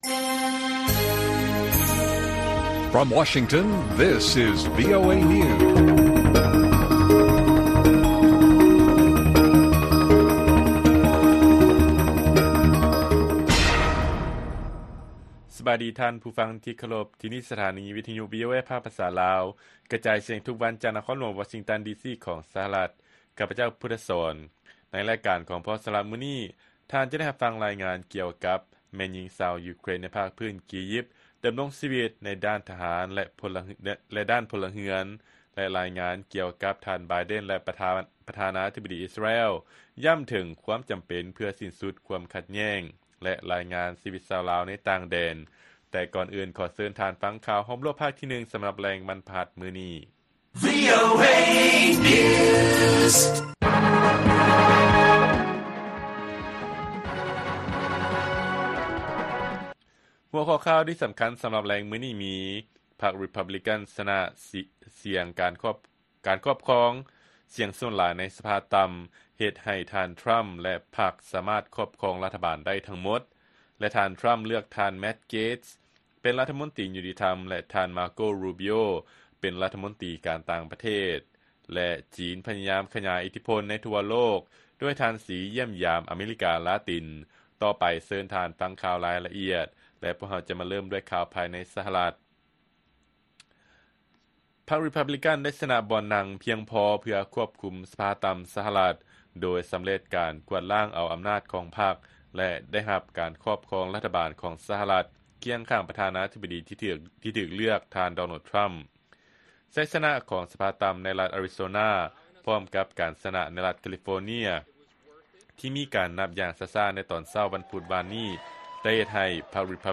ລາຍການກະຈາຍສຽງຂອງວີໂອເອລາວ: ພັກຣີພັບບລີກັນ ຊະນະສຽງການຄອບຄອງສຽງສ່ວນຫຼາຍໃນສະພາຕ່ຳ ເຮັດໃຫ້ທ່ານ ທຣຳ ແລະ ພັກສາມາດຄອບຄອງລັດຖະບານໄດ້ທັງໝົດ